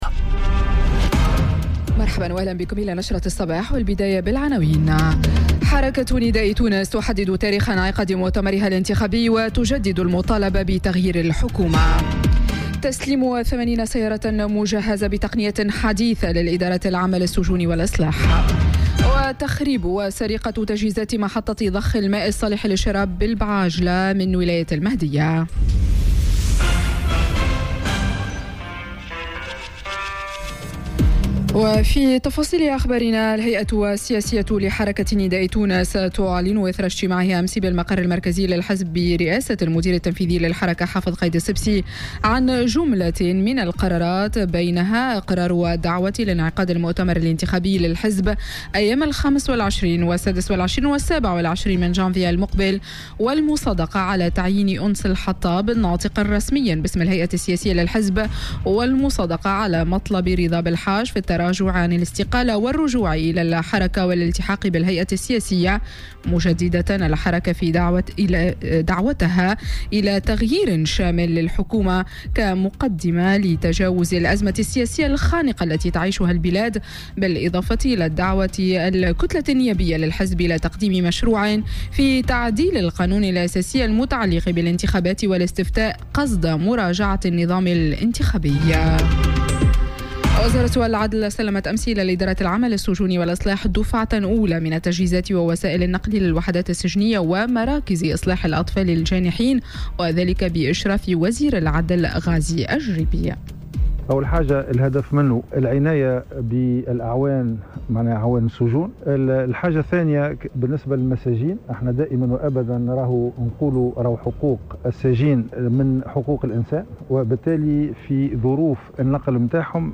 نشرة أخبار السابعة صباحا ليوم السبت 4 أوت 2018